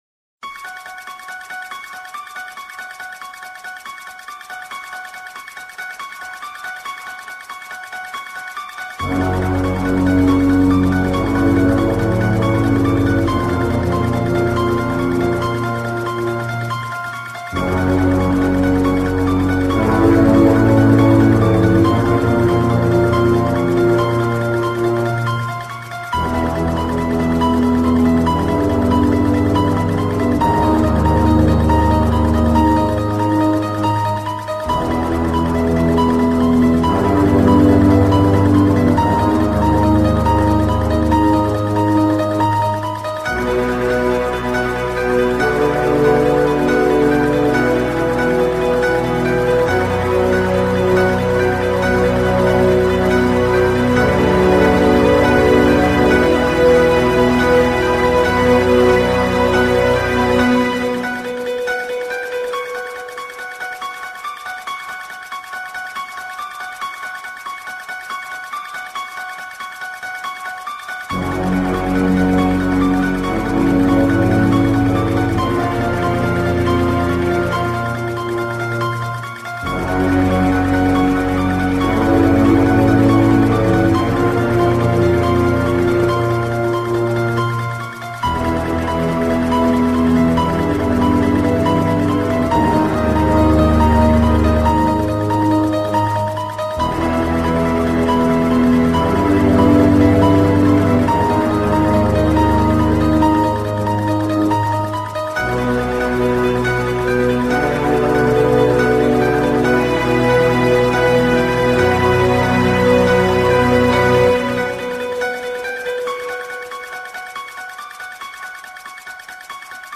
اهنگ بی کلام